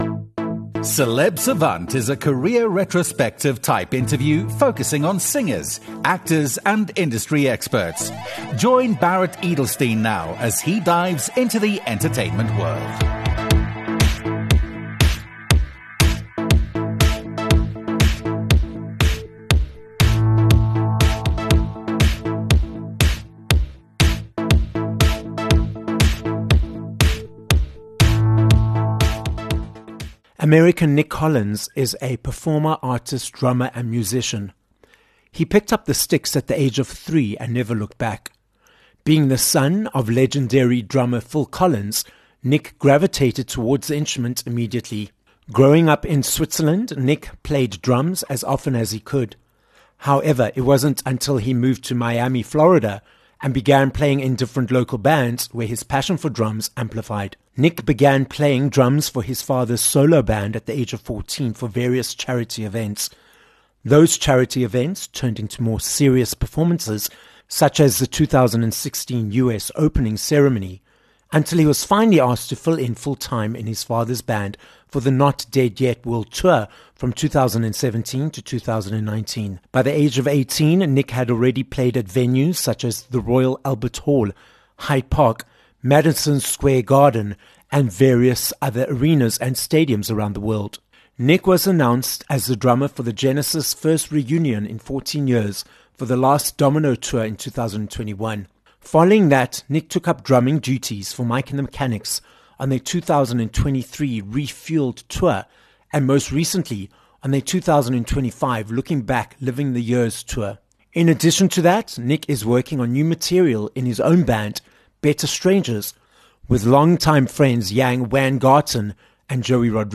Nic Collins - an American drummer, songwriter, musician and member of the band Better Strangers - joins us on this episode of Celeb Savant.